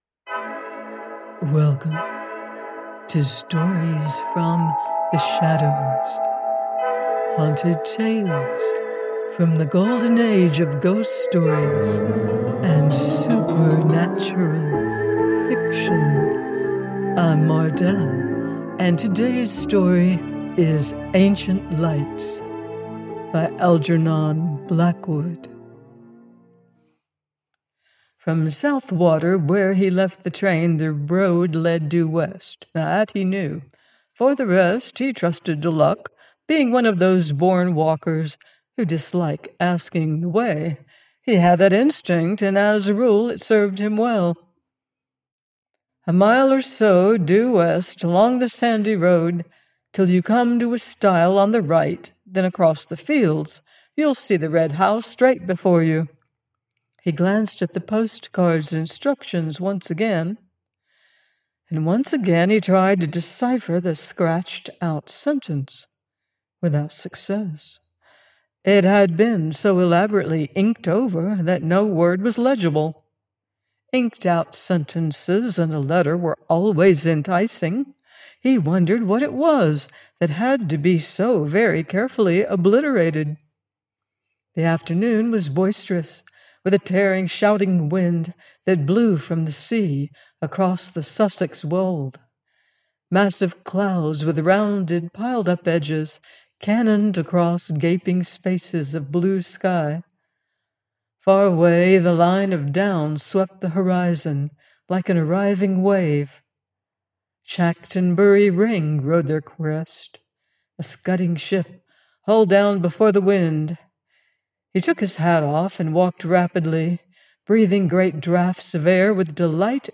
Ancient Lights – by Algenon Blackwood - audiobook